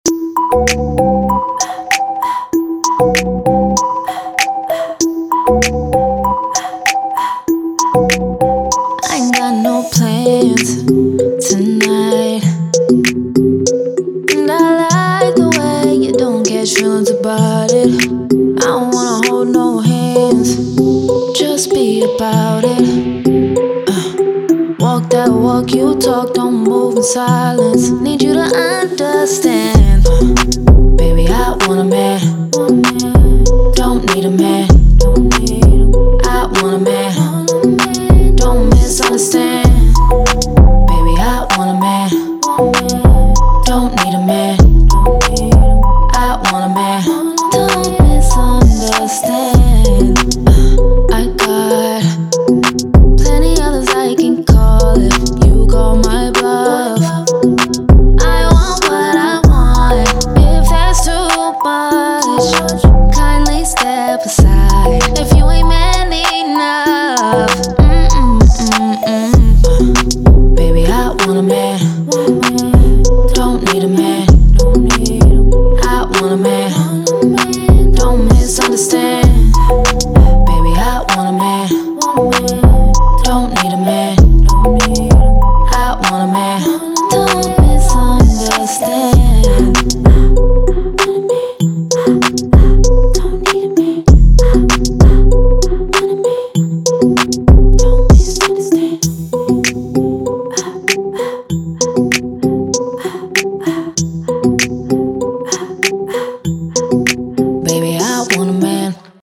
R&B
E minor